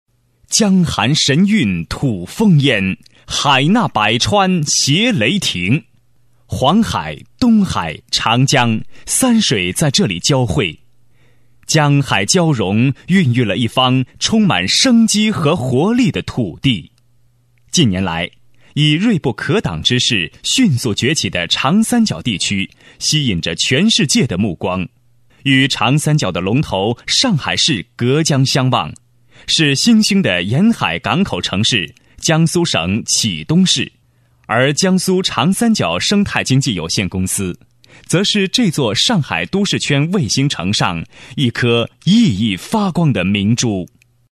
专题男305号（年轻干练）
激情力度 企业专题,人物专题,医疗专题,学校专题,产品解说,警示教育,规划总结配音
磁性年轻男音，擅自新闻，多媒体汇报，专题汇报等题材。